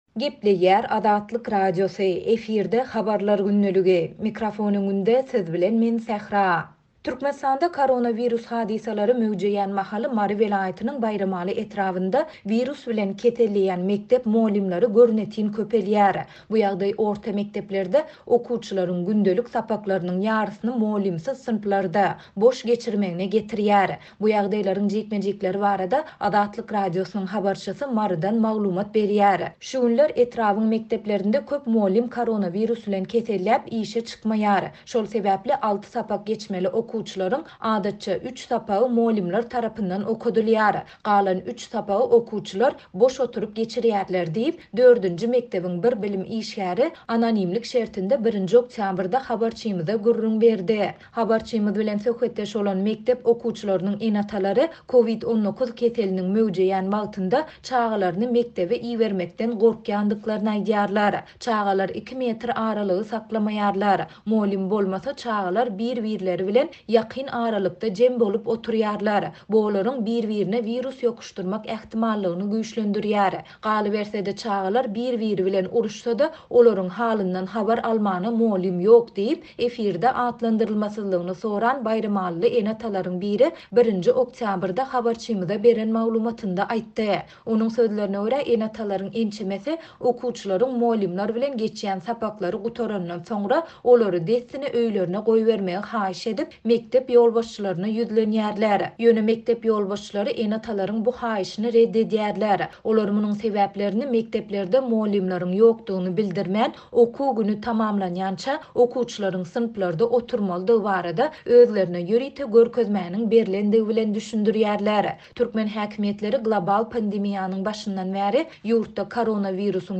Bu ýagdaý orta mekdeplerde okuwçylaryň gündelik sapaklarynyň ýarysyny mugallymsyz synplarda, boş geçirmegine getirýär. Bu ýagdaýlaryň jikme-jikleri barada Azatlyk Radiosynyň habarçysy Marydan maglumat berýär.